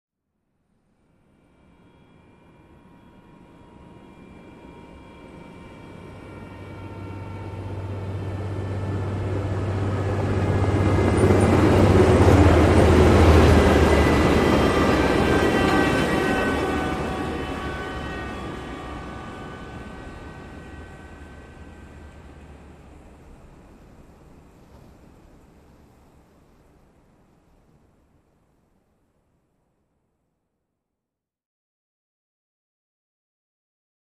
Train By Smooth With Electric Whir, Rail Clicks, And High Pitched Whine